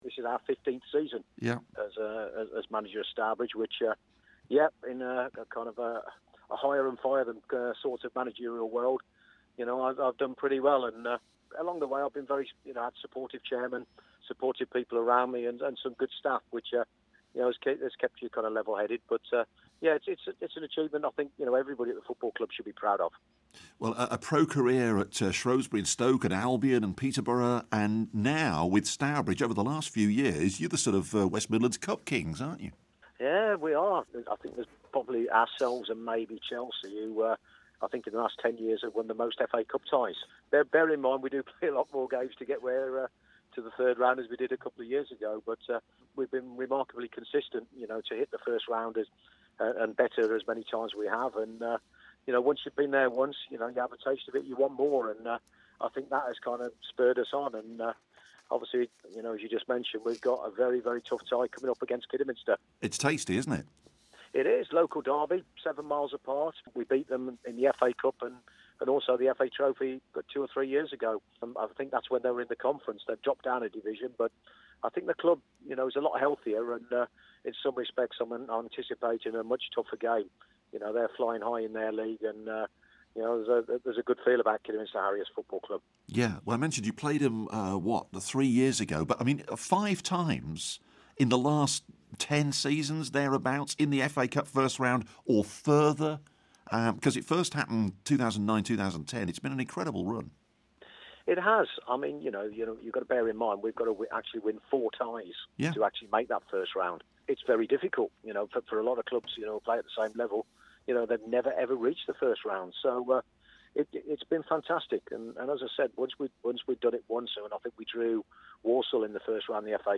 Non-League Interview